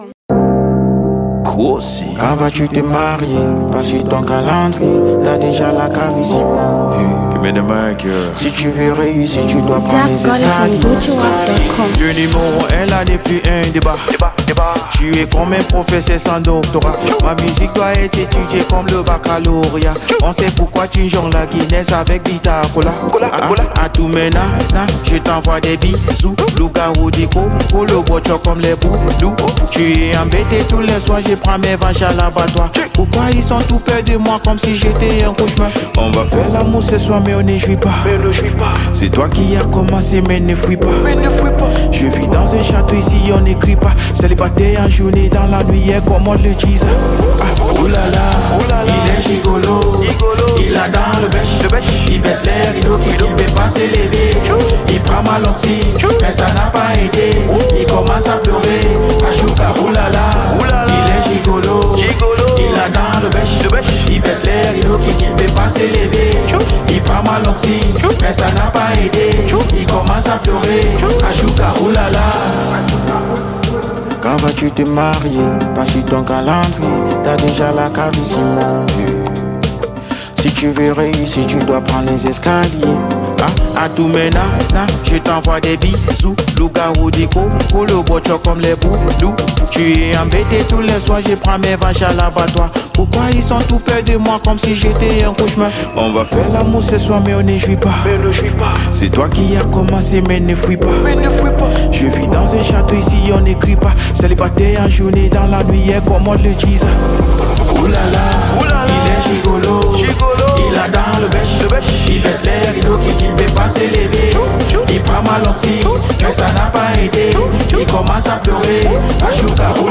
a captivating blend of Afro-pop and R&B
soulful vocals and artistry.